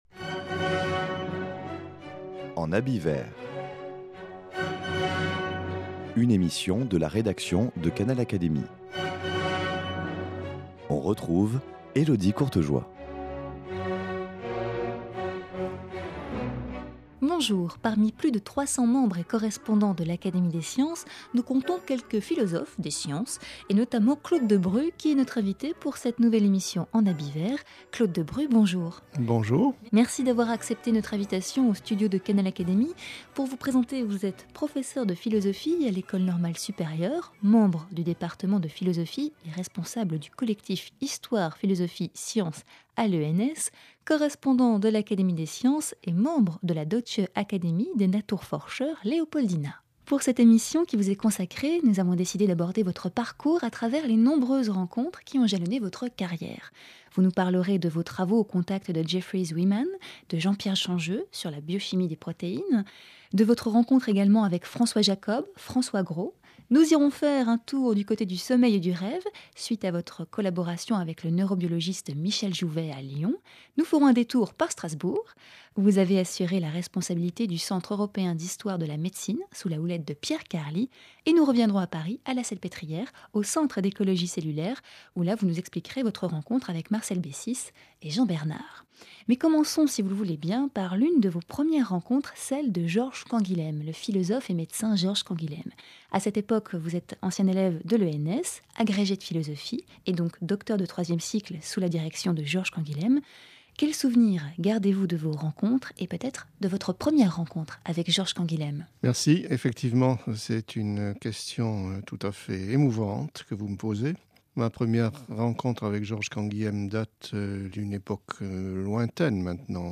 Persuadé que la philosophie des sciences se pratique au plus près des chercheurs, il a mis la main à la pâte, opérant des rats, enregistrant des kilomètres d’électroencéphalogramme, ou travaillant dans les services d’hématologie à hôpital. Rencontre.